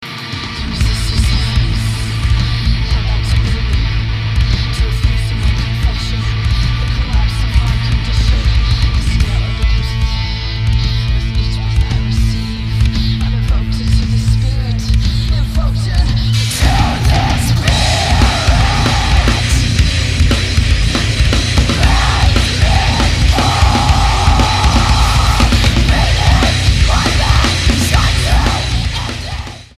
STYLE: Hard Music
This is loud and intense and on the whole very good.
Hardcore with a bit of diversity.